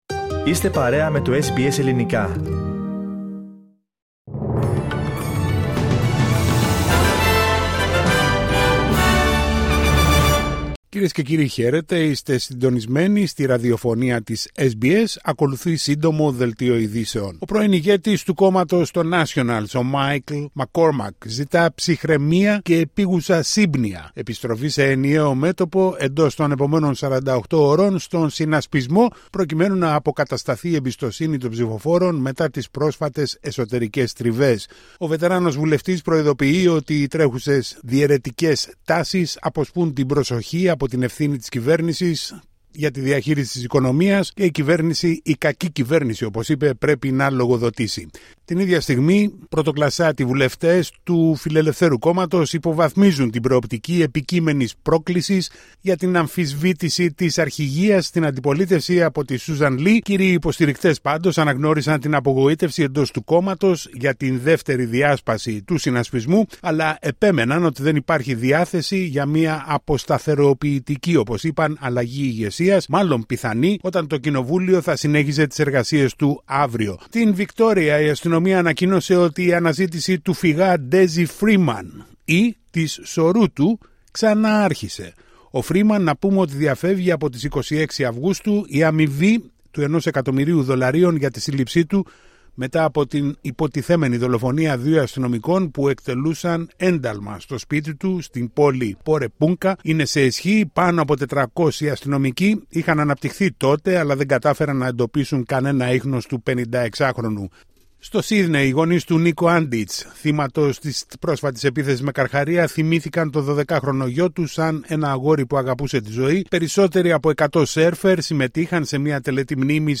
Σύντομο δελτίο ειδήσεων στα Ελληνικά από την Αυστραλία την Ελλάδα την Κύπρο και όλο τον κόσμο